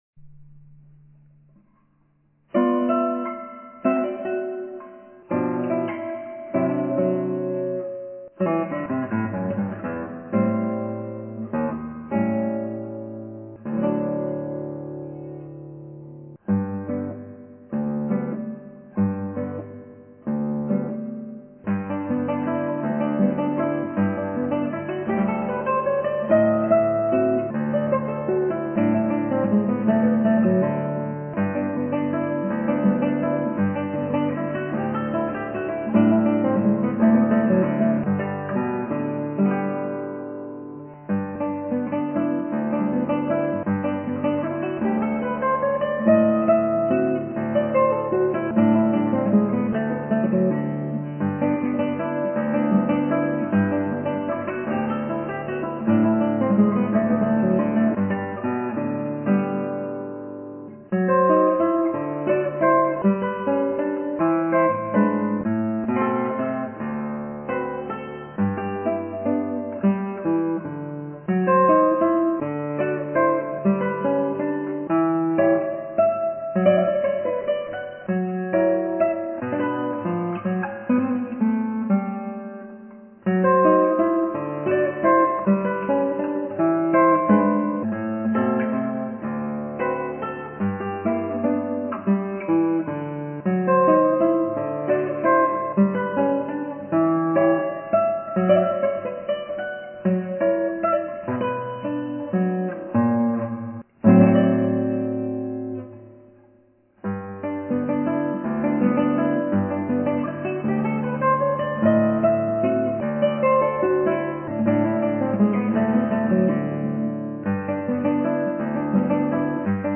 ギターは尾野ギター